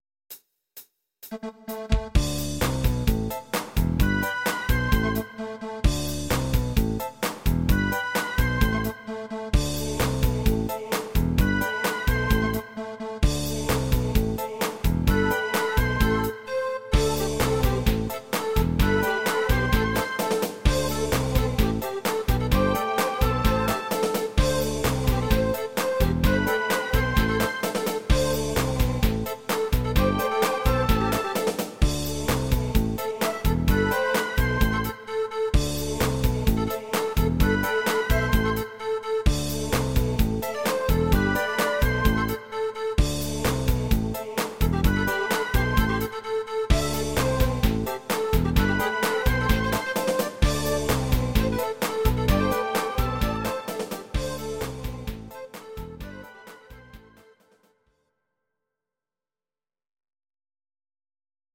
Am
Audio Recordings based on Midi-files
Pop, 1990s